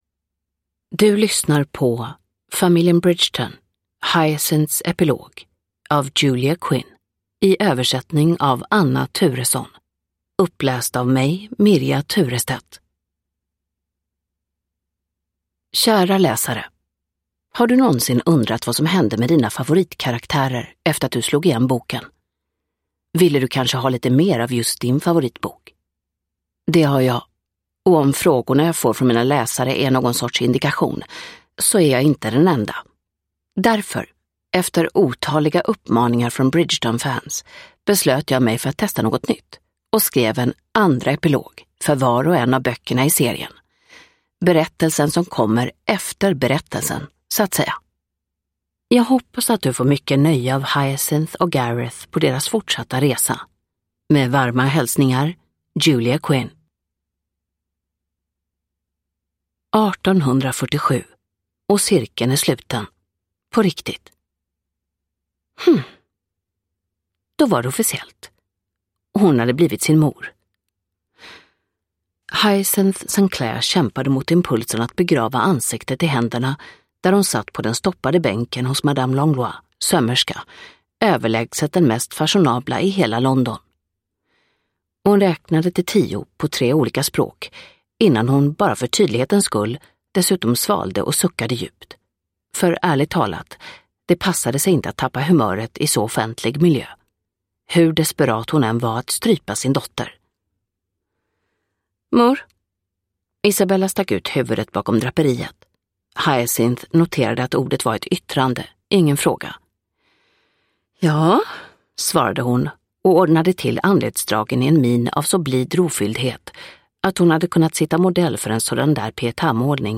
Hyacinths epilog – Ljudbok – Laddas ner